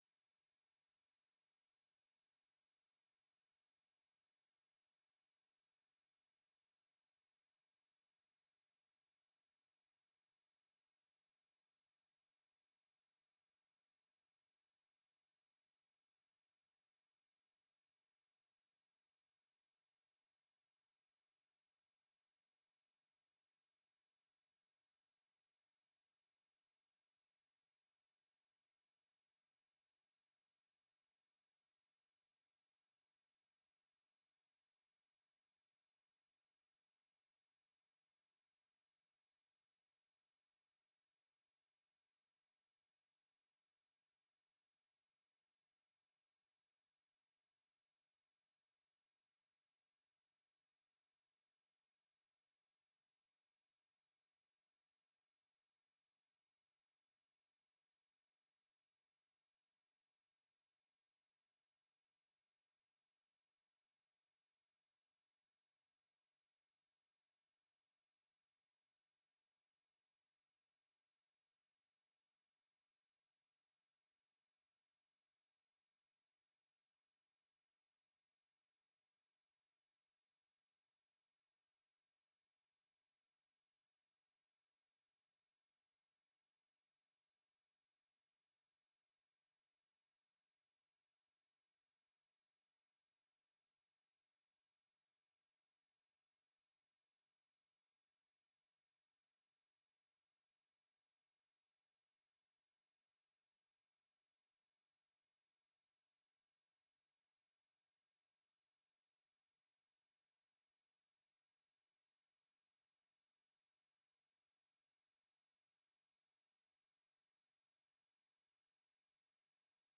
Raadsvergadering 03 oktober 2024 20:00:00, Gemeente Ouder-Amstel